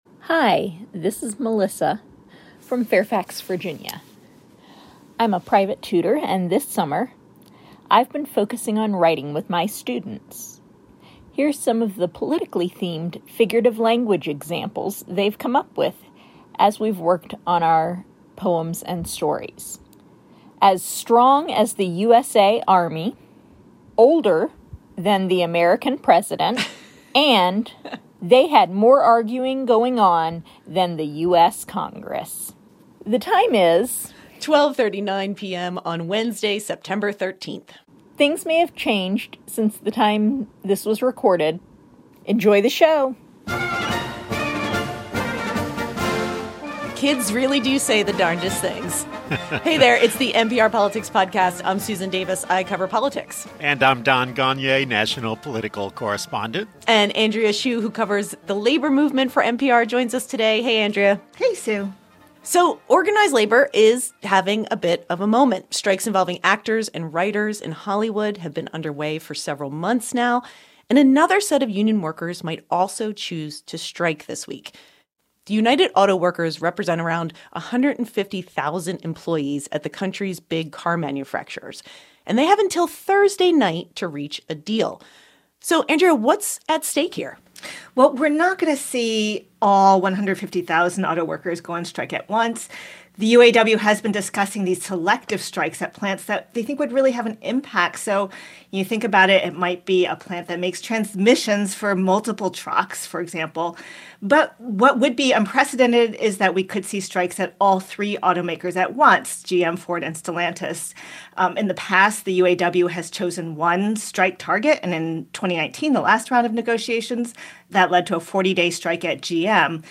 political correspondents
labor & workplace correspondent